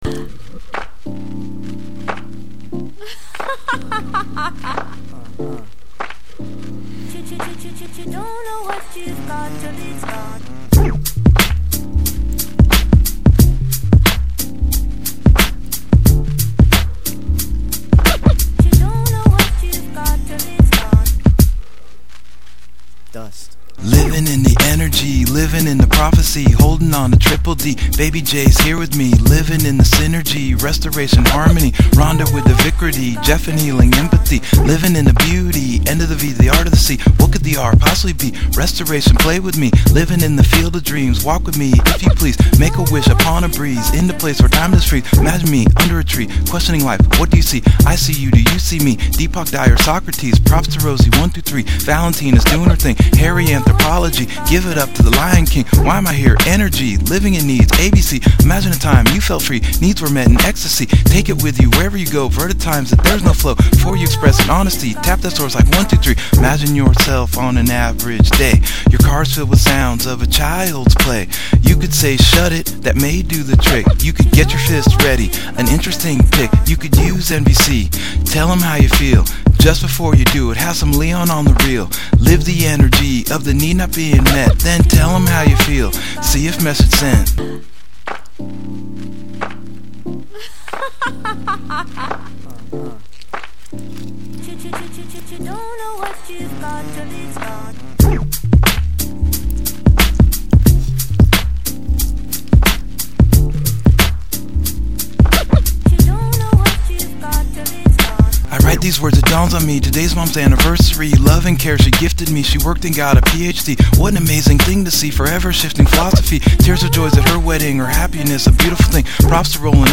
NVC Hip Hop ‘Living In The Energy Of Needs’